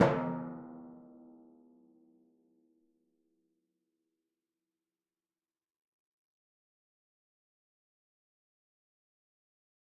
Timpani4_Hit_v4_rr2_Sum.mp3